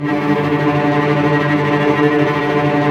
Index of /90_sSampleCDs/Roland LCDP13 String Sections/STR_Vcs Tremolo/STR_Vcs Trem f